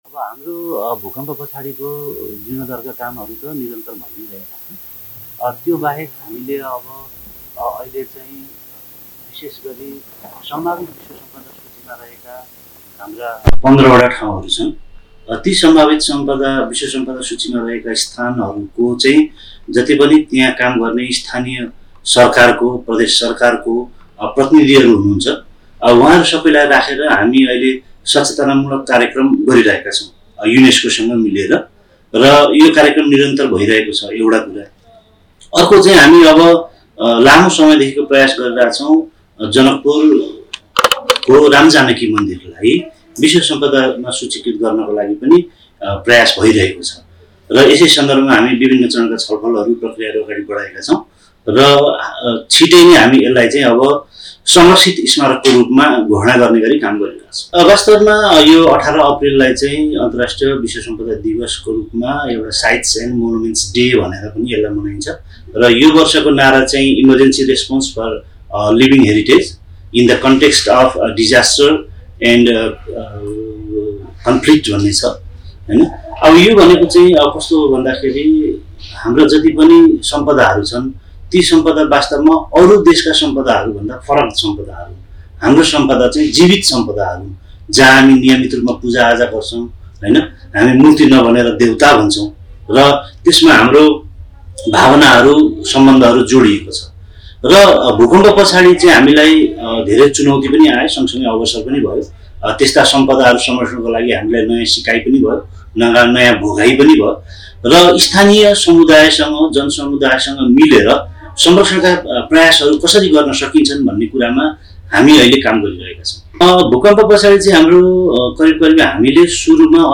विशेष कुराकानीको क्रममा उनले प्रारम्भिक अध्ययनमा ९२० वटा सम्पदा क्षतिग्रस्त भएको देखिएको थियो भने, पछि २१५ वटा गुम्बा थपिएर हालसम्म ८१५ वटा सम्पदाको पुनर्निर्माण सम्पन्न भइसकेको बताए ।